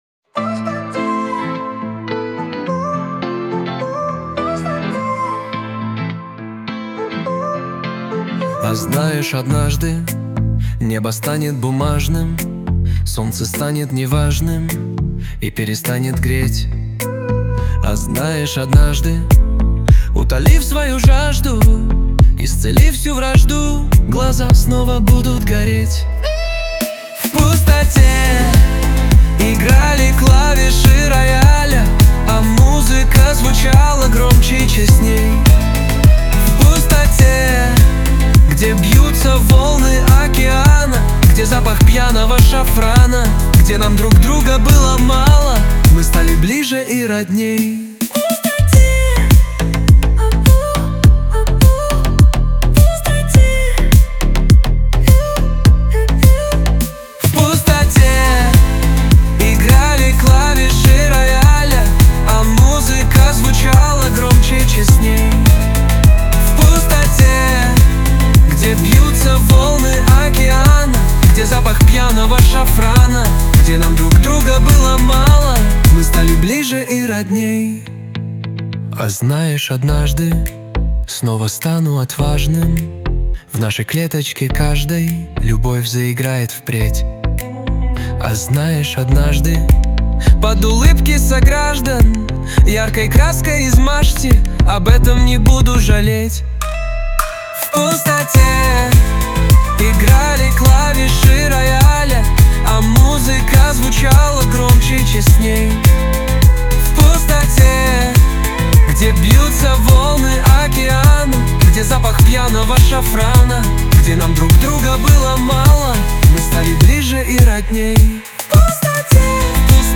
эстрада
pop
диско